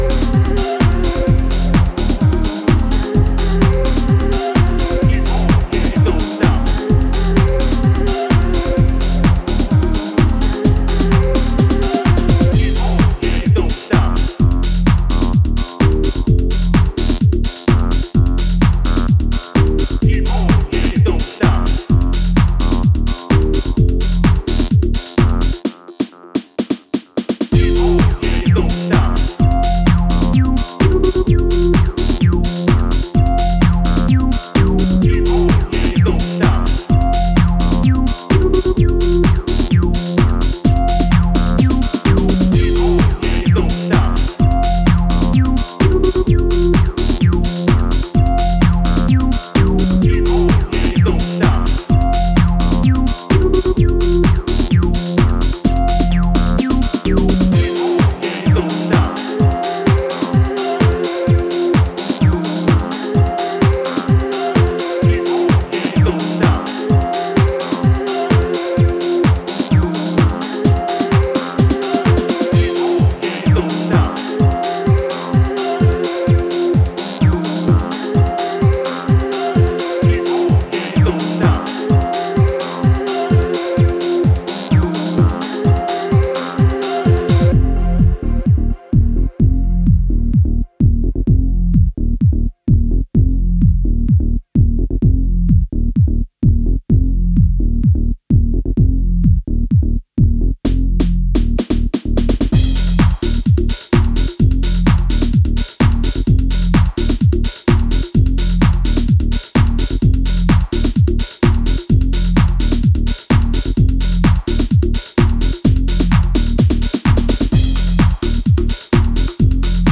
Hard-hitting grooves and pure nostalgia guaranteed.